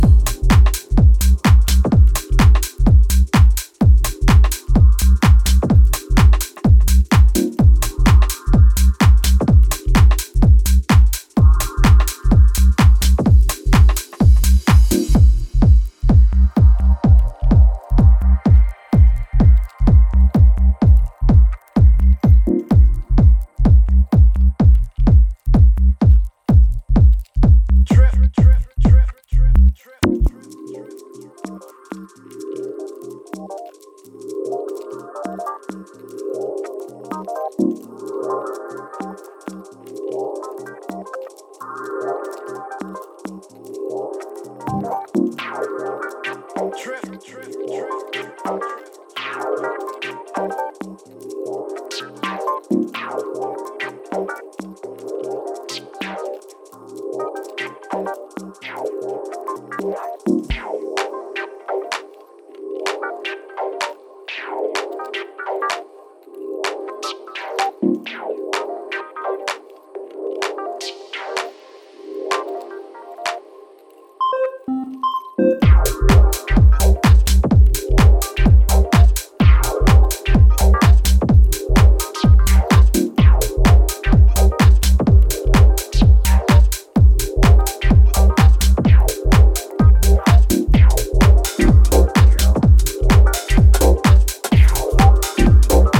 Old school UKG to Dub Techno and Minimal grooves